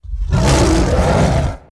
Gorilla.wav